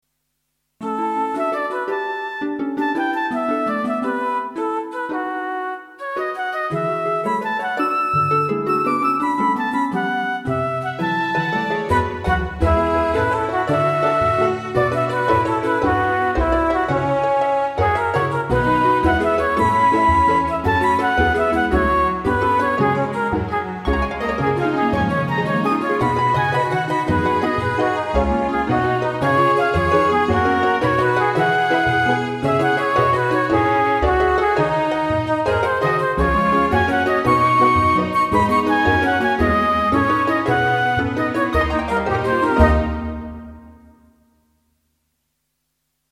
klassiek